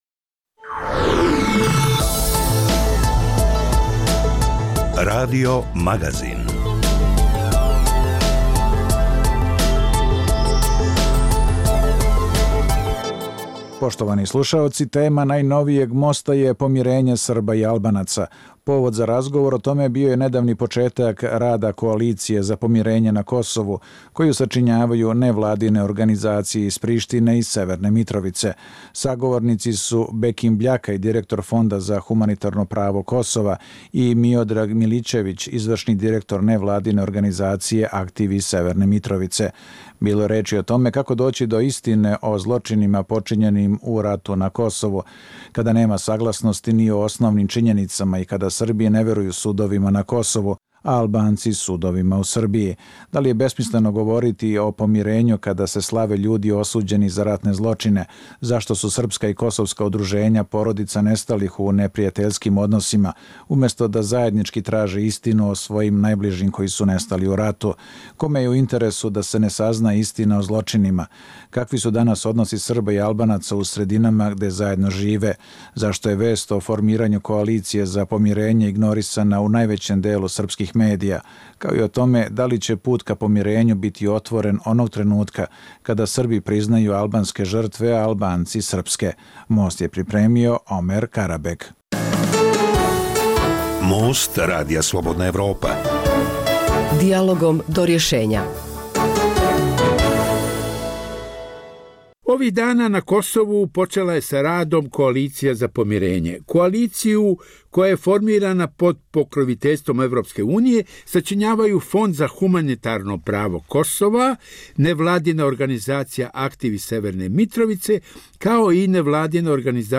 Dijaloška emisija o politici, ekonomiji i kulturi.